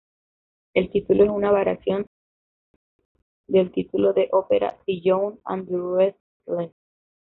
va‧ria‧ción
/baɾjaˈθjon/